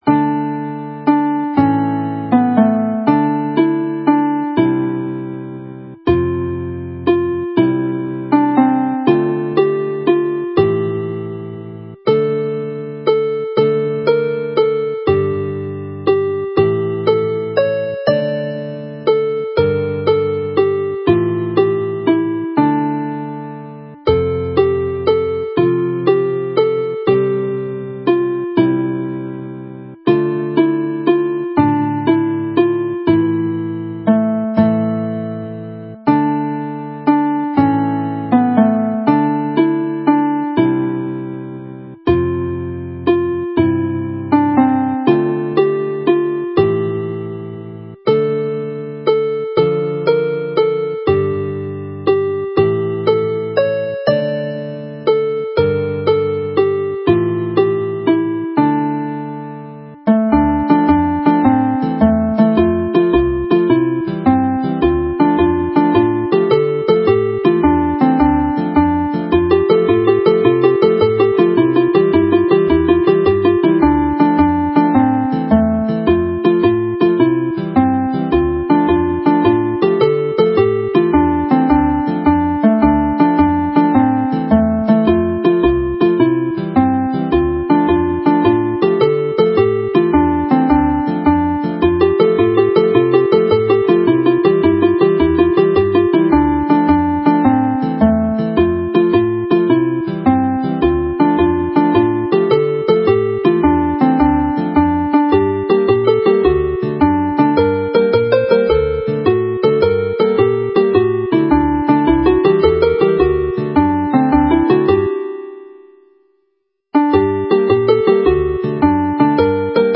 Mae'r set hon wedi'i seilio ar gywair D leiaf, gan newid i fod yn fwy llawen D fwyaf i gloi.
This set is based on the key of D minor, with a cheering change to D major to close.
Tros y Garreg is a well-known traditional Welsh song commemorating the contribution made by Welsh soldiers to the accession of Henry 7th to the throne.
The set ends in a happy mood with Marchogion Eryri was first presented in the April 2012 set in this collection.